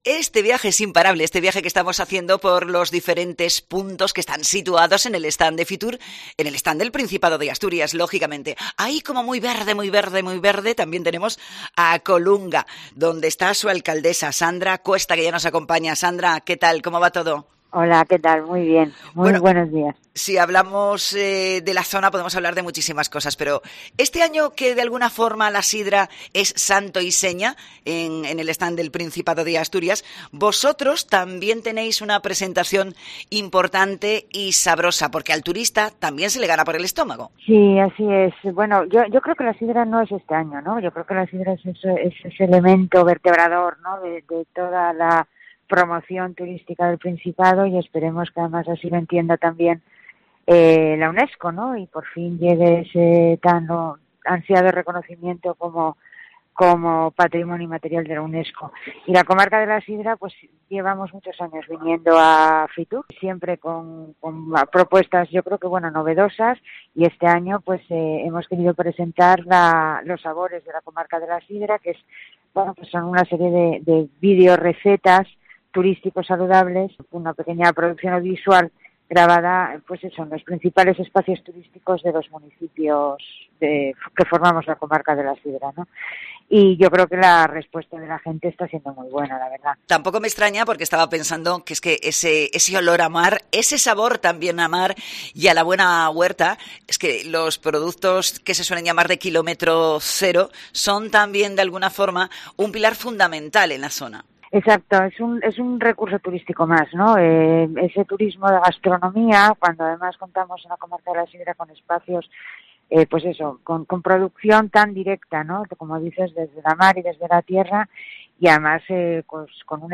La alcaldesa, Sandra Cuesta, ha participado en el programa especial de COPE Asturias desde IFEMA Madrid con motivo de la Feria Internacional del Turismo Fitur
Fitur 2022: Entrevista a la alcaldesa de Colunga, Sandra Cuesta